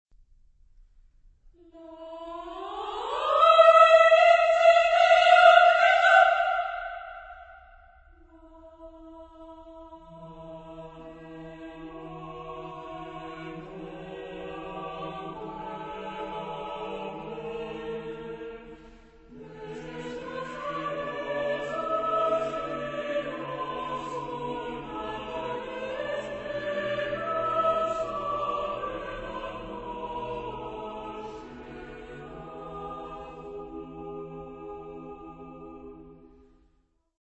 Genre-Style-Form: Secular ; Poem
Mood of the piece: fast ; contrasted ; dissonant
Type of Choir: SSAATBB  (7 mixed voices )
Tonality: E tonal center
Consultable under : 20ème Profane Acappella